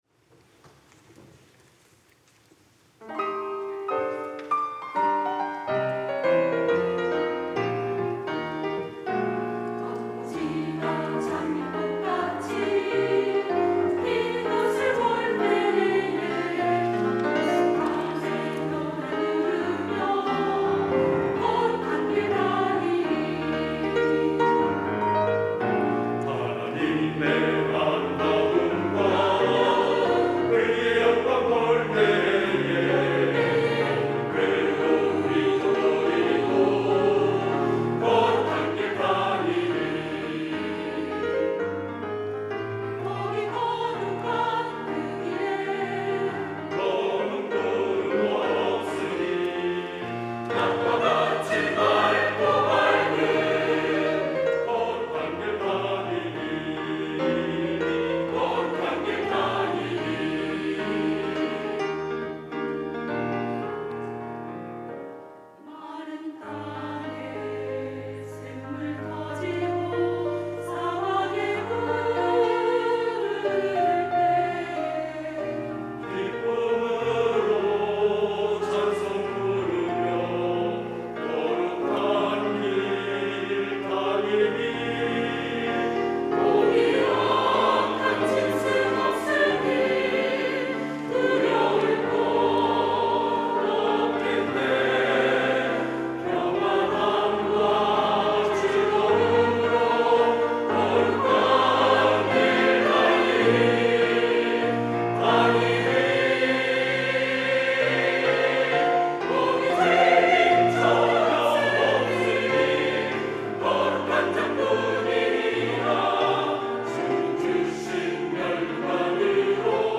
할렐루야(주일2부) - 황무지가 장미꽃 같이
찬양대